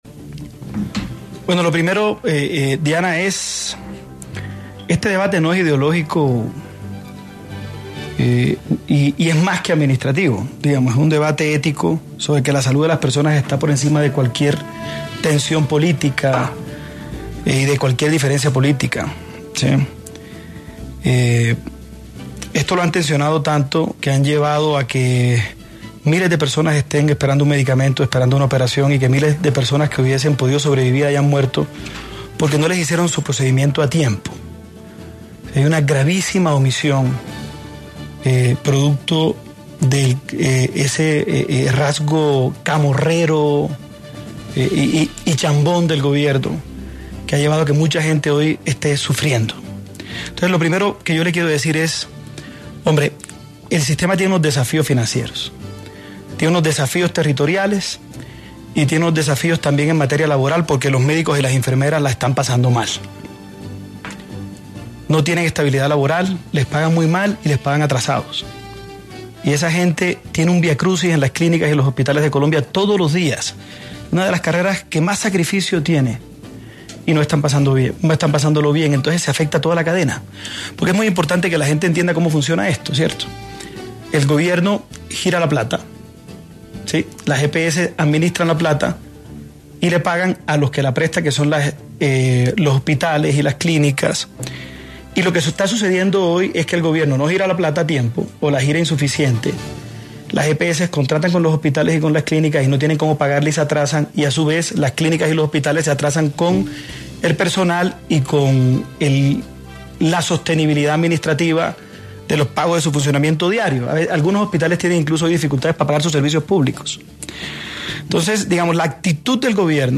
En 5x20 de Hora 20, un programa de Caracol Radio, estuvo el precandidato, Héctor Olimpo, quien hablo sobre regionalizar el modelo que existe, y a su vez, explicó y planteó lo que él haría si fuera presidente con la salud en Colombia, la cual ha sido muy controversial en los últimos meses.